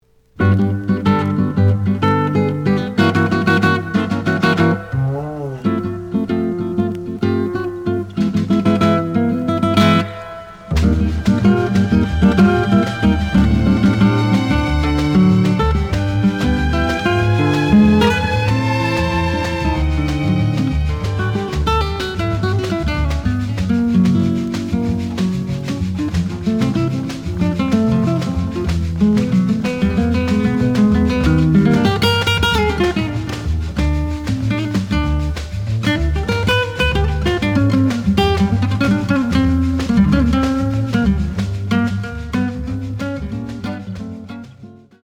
The audio sample is recorded from the actual item.
●Genre: Bossa Nova